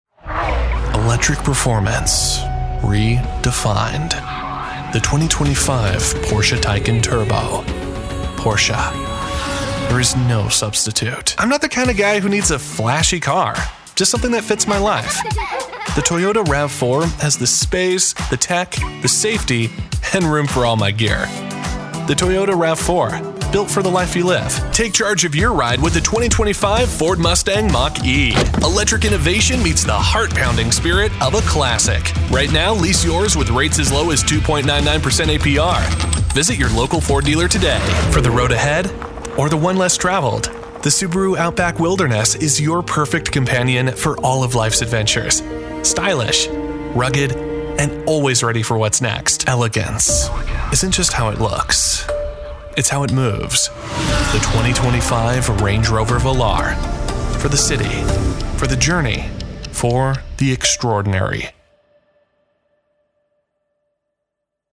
American Voice Over Talent
Adult (30-50) | Yng Adult (18-29)